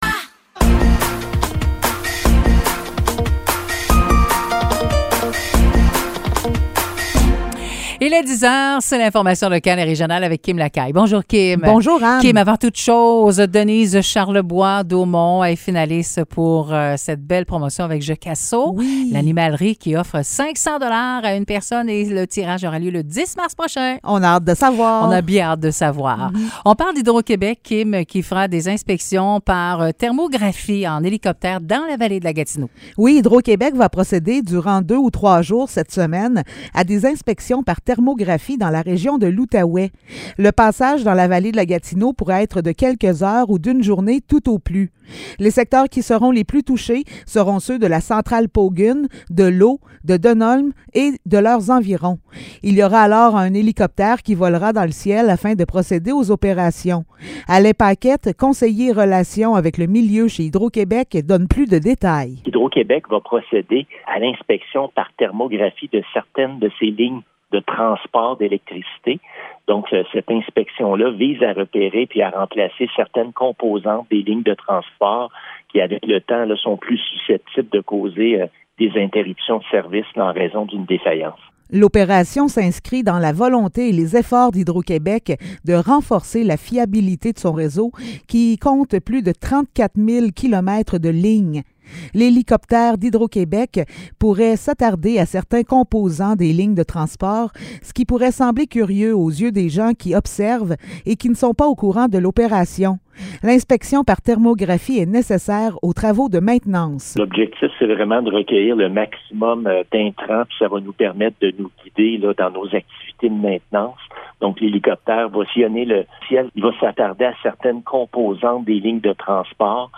Nouvelles locales - 20 février 2023 - 10 h